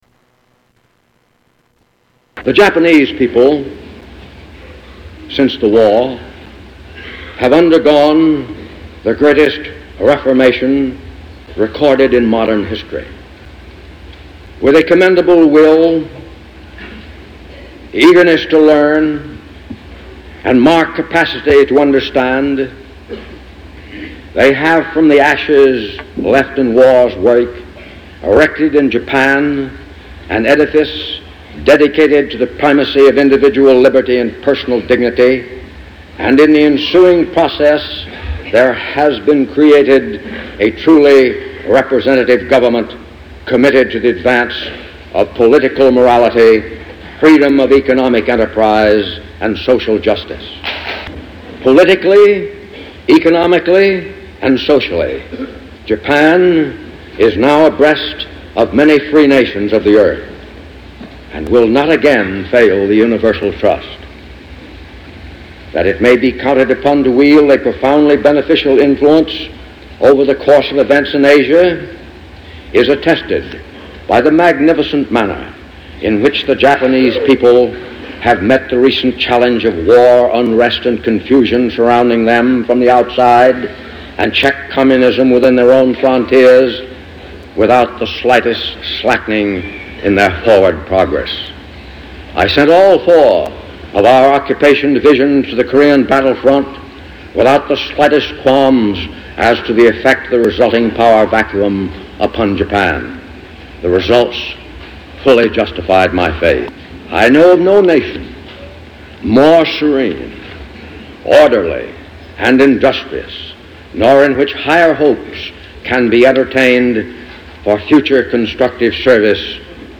Tags: Historical General Douglas Mac Arthur Worl War II Farewell Address